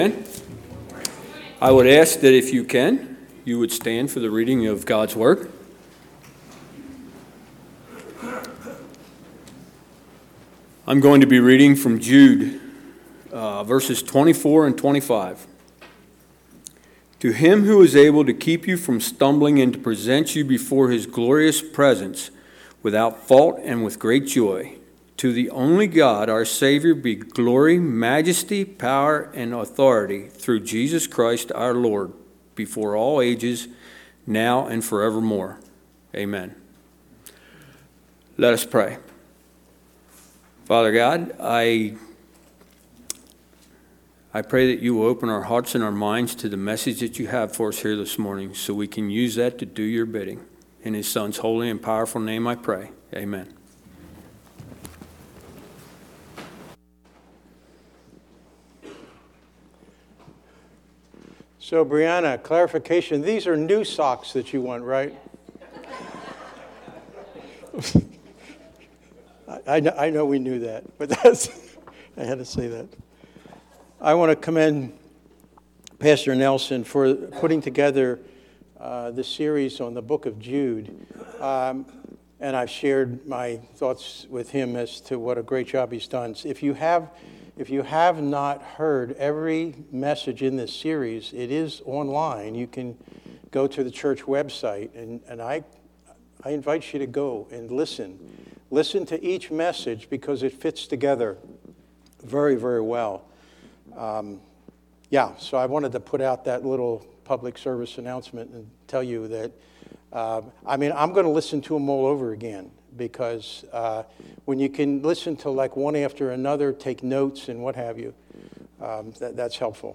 A message from the series "October 2025."